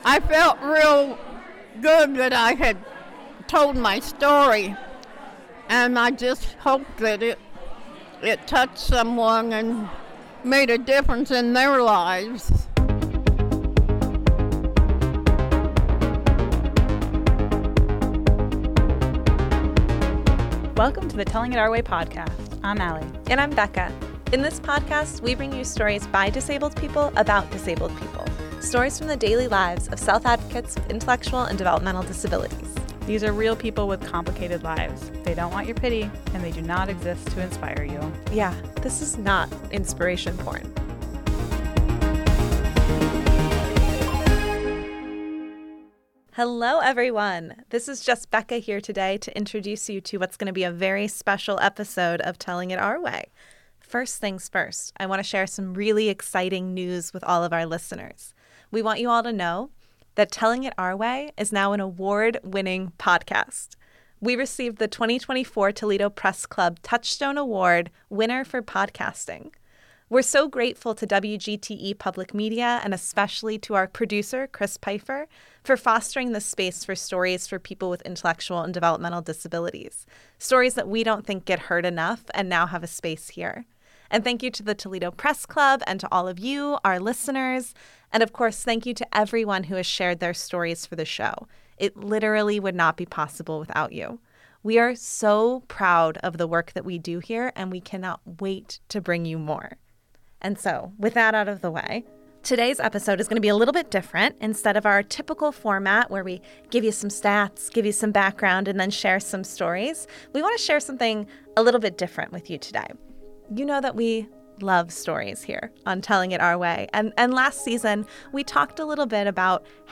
In this special episode, we take you inside a public storytelling event that took place for Disability Pride Month at the Toledo Museum of Art this summer. We hear from audience members and storytellers about why sharing our stories matters, especially for people with intellectual and developmental disabilities.